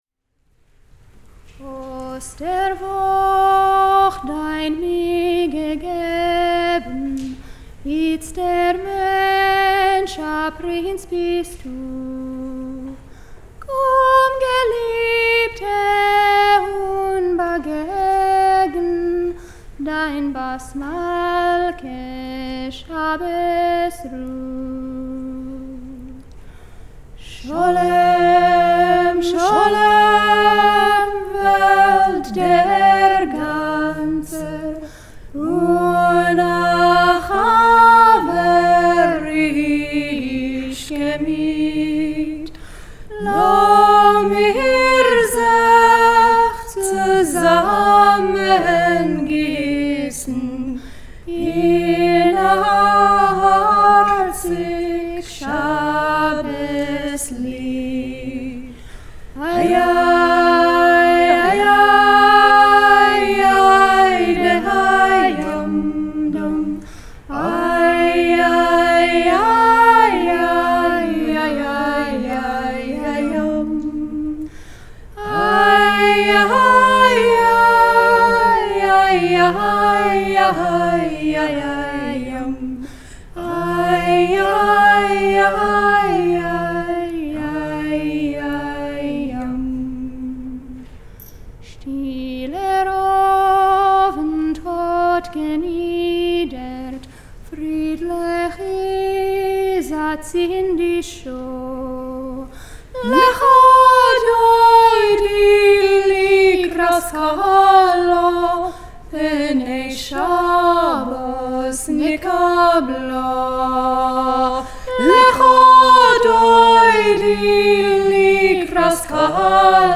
a Yiddish song welcoming the peace of Shabbat
vocals (recorded live